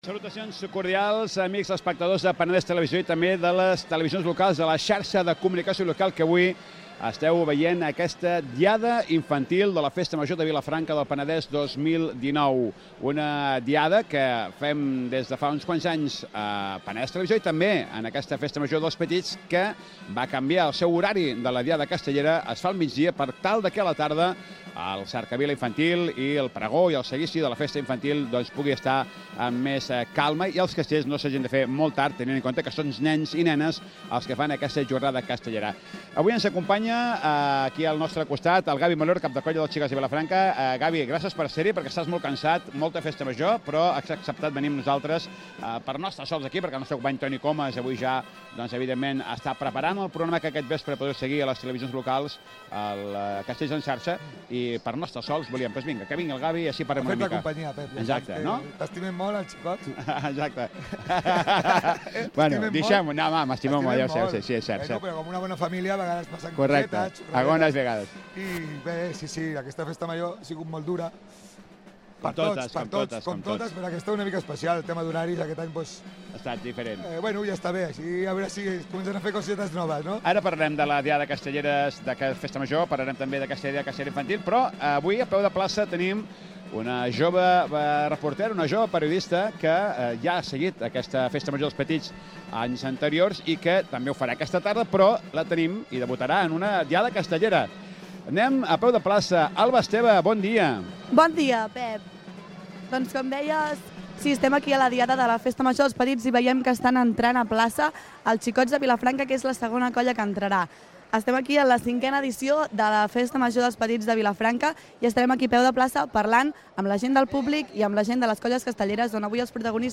Inici de la transmissió de la diada castellera dels petits a la Festa Major de Vilafranca. Ambient a la plaça, ordre d'intervencó de les colles castelleres